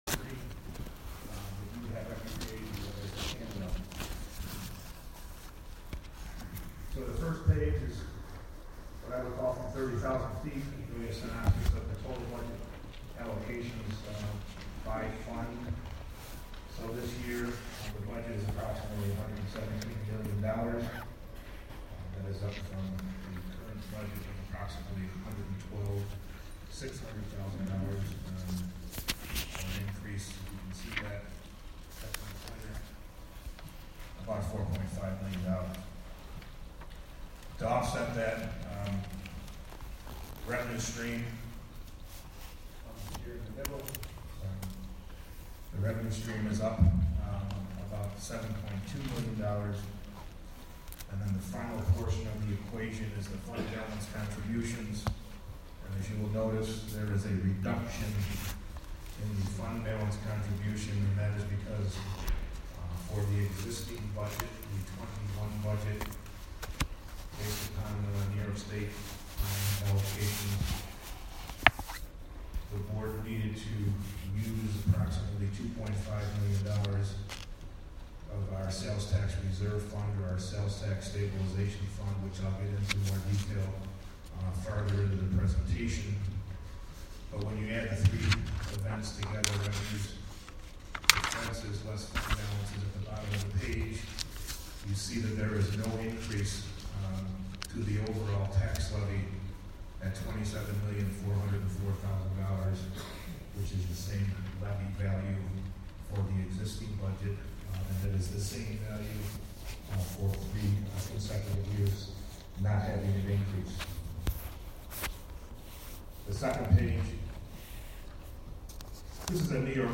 The Greene County Legislature holds a public hearing on the tentative County budget for 2022. Streaming live from the Catskill High School auditorium on Oct. 27, 2021.